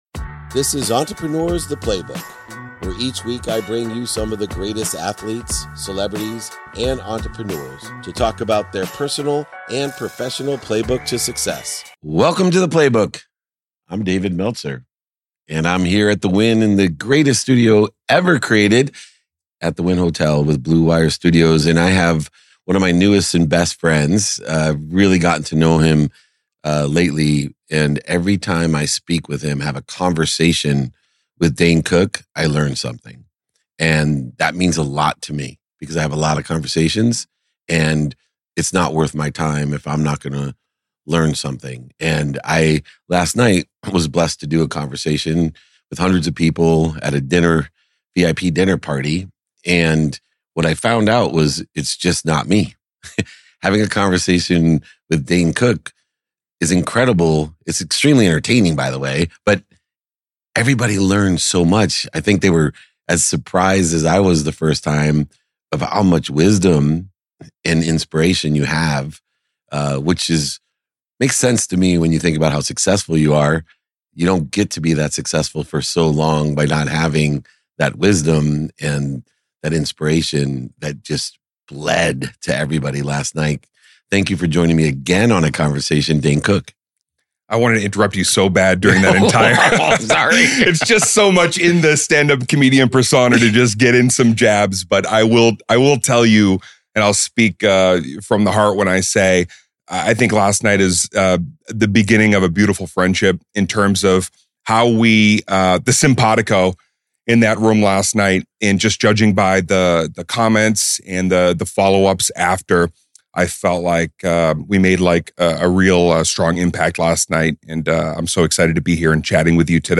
Today's episode features an enlightening conversation with the incredibly talented stand-up comedian and actor, Dane Cook.